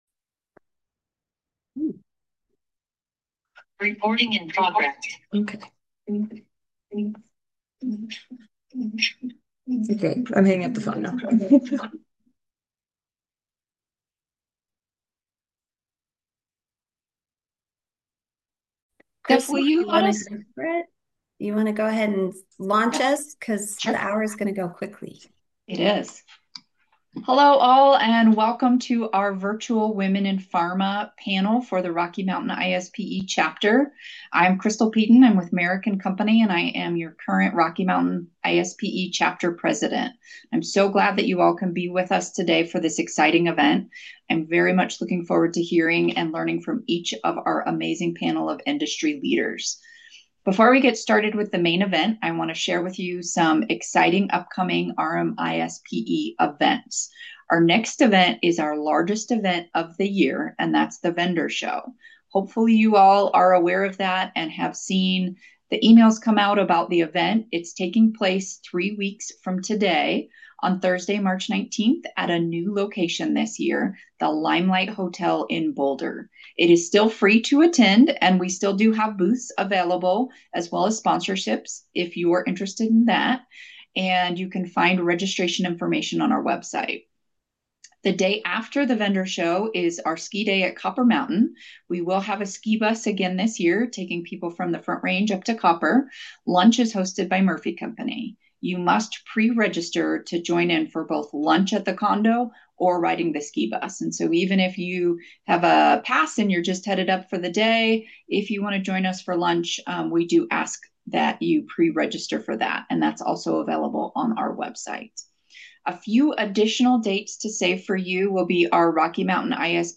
Thank you to all that attended our 2026 Women in Pharma panel!